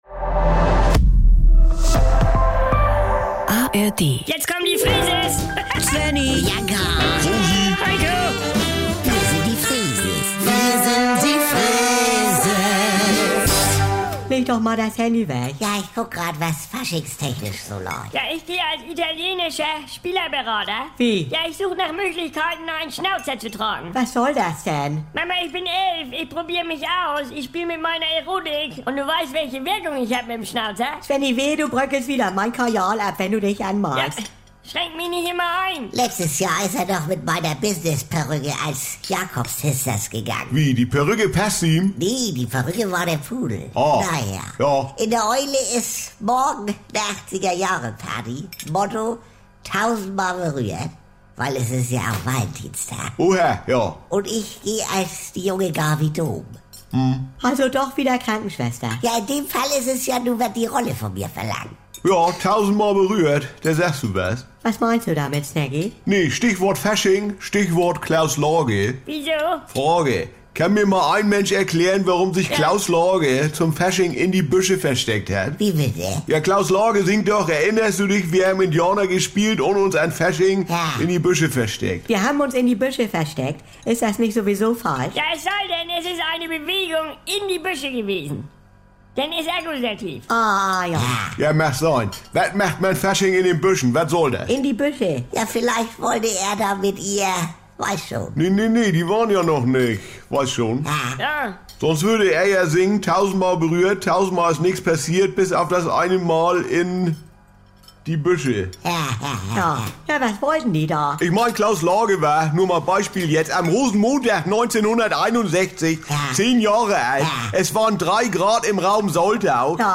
Warum hat sich Klaus Lage in seinem Song "1000x berührt" eigentlich "in die Büsche" versteckt? Jederzeit und so oft ihr wollt: Die NDR 2 Kult-Comedy direkt aus dem Mehrgenerationen-Haushalt der Familie Freese.